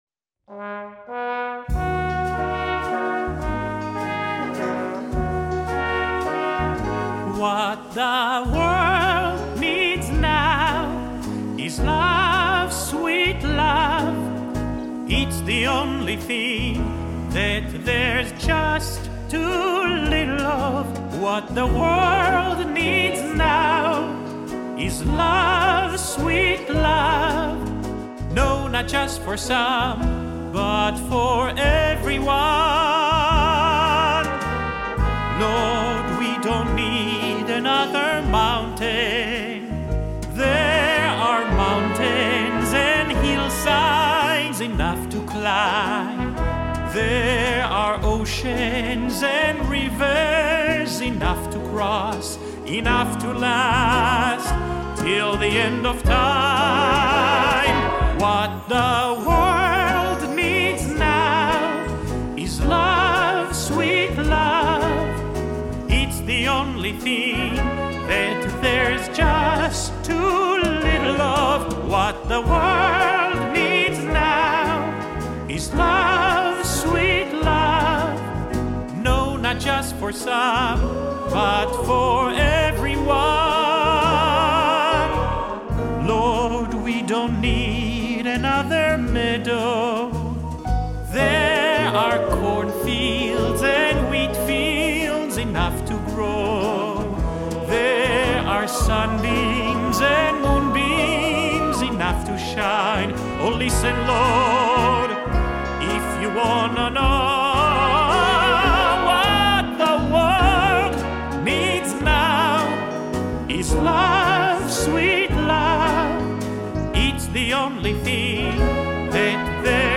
Recorded live on the High Holidays at Park Avenue Synagogue, 2023